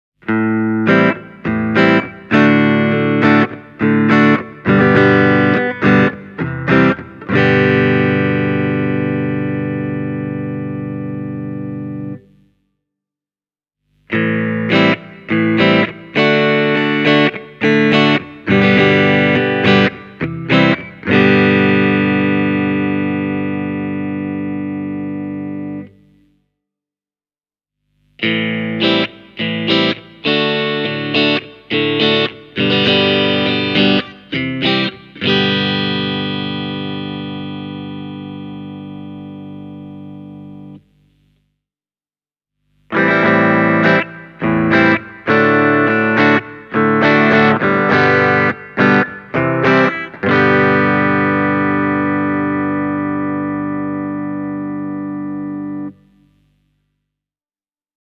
Here’s an example of the clean magnetic tones you can get from this Music Man. The powerful DiMarzio Illuminators are loaded with ceramic magnets (neck PU –> both (full) –> both (coil split) –> bridge PU):
music-man-petrucci-majesty-e28093-mag-cleans.mp3